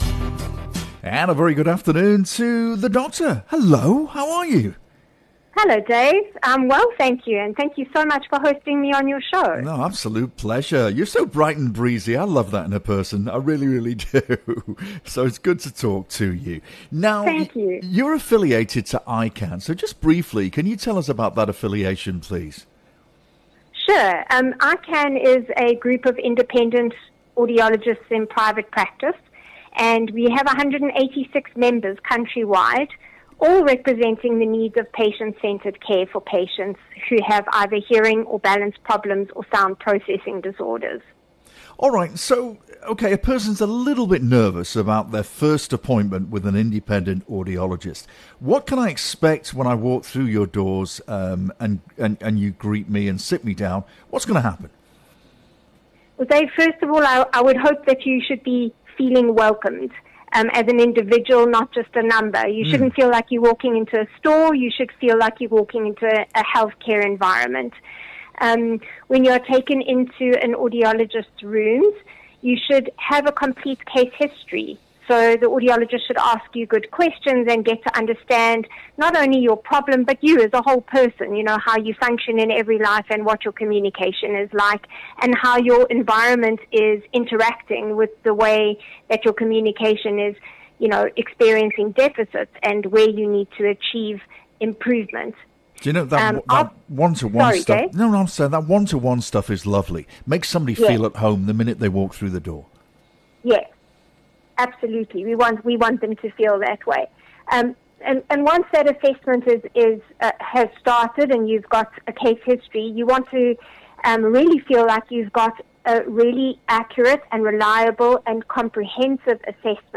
This ICAN interview explores what makes independent audiologists unique, covering the first appointment experience, personalised hearing aid selection, differences between devices, ongoing follow-up support, additional assistive solutions, how to encourage and support loved ones with hearing loss, when to test your hearing, and where to find a trusted independent audiologist near you.